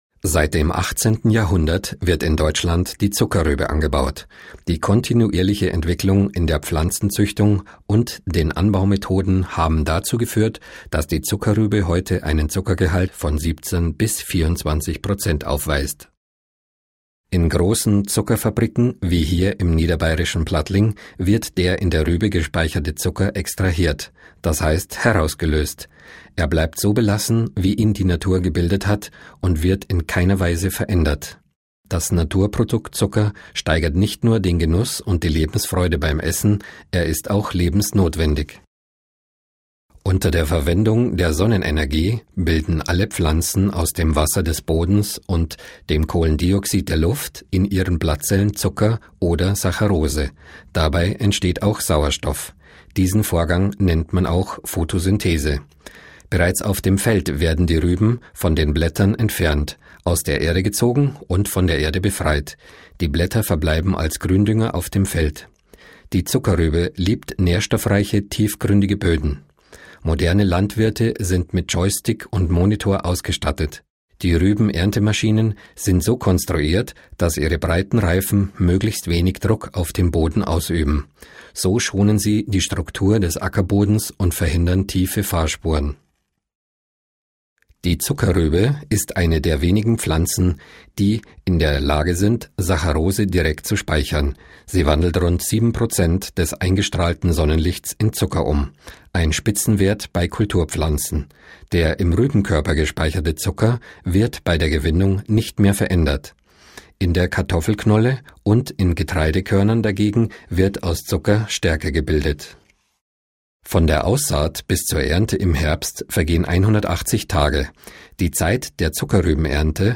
Werbung FAS Frankfurter Allgemeine